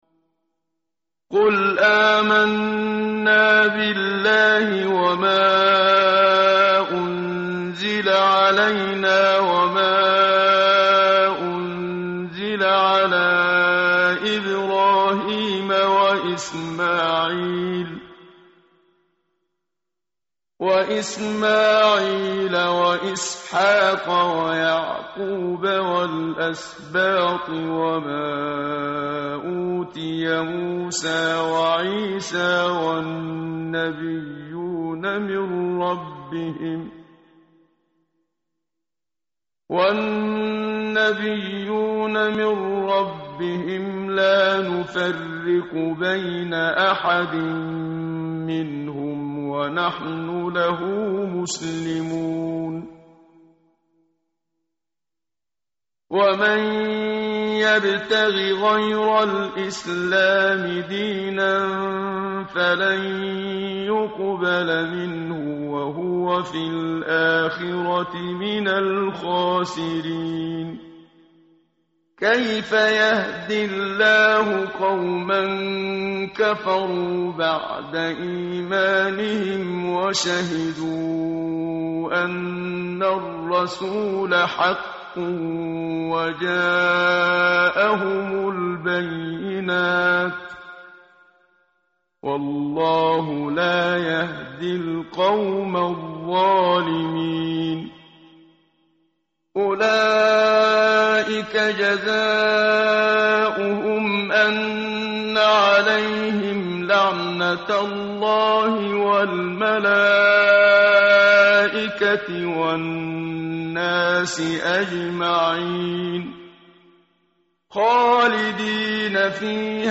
tartil_menshavi_page_061.mp3